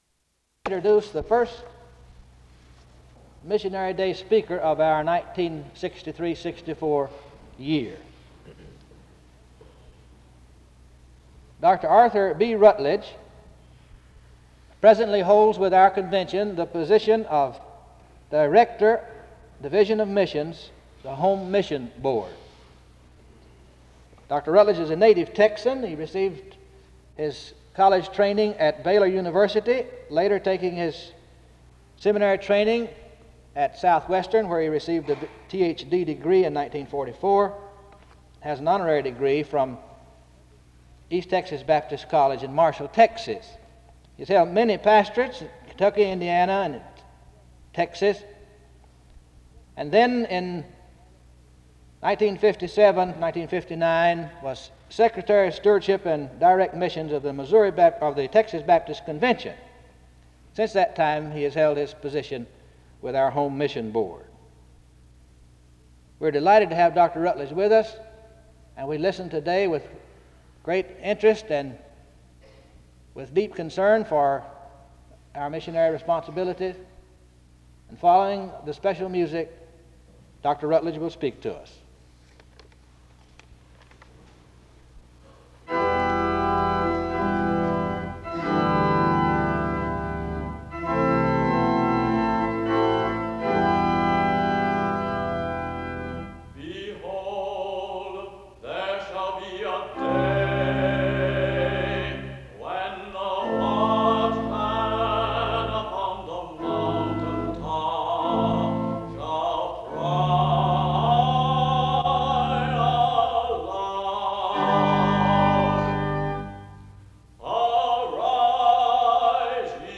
There is special music from 1:25-7:00.
SEBTS Chapel and Special Event Recordings